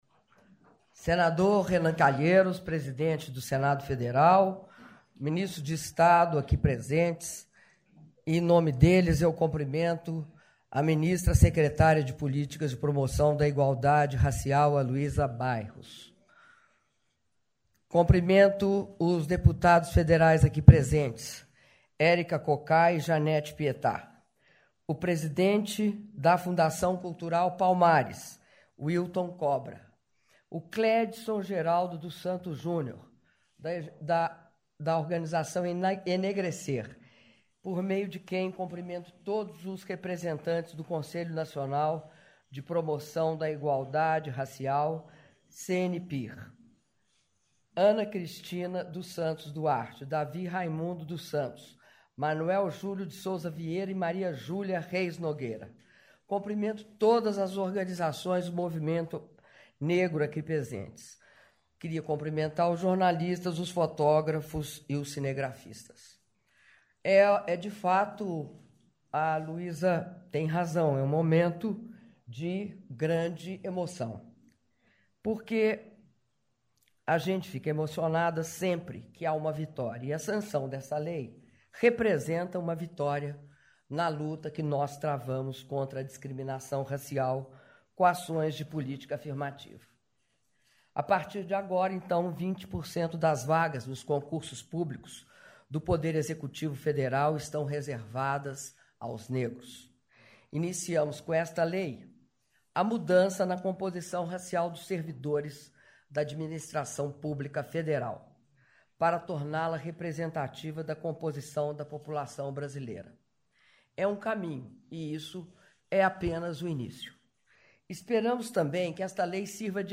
Áudio do discurso da Presidenta da República, Dilma Rousseff, durante cerimônia de sanção da Lei que dispõe sobre cotas no Serviço Público Federal (05min18s)